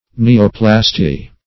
neoplasty - definition of neoplasty - synonyms, pronunciation, spelling from Free Dictionary